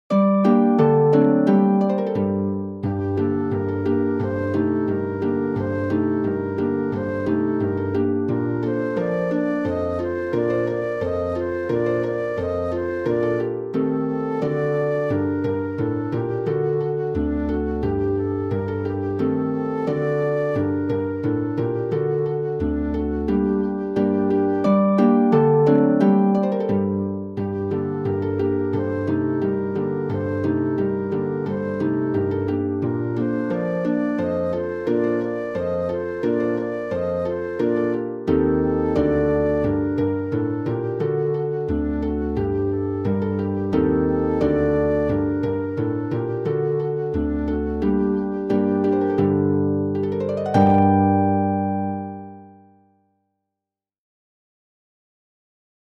• Two pedal harps and flute: $10.00
• Pedal harp, lever harp, and flute: $10.00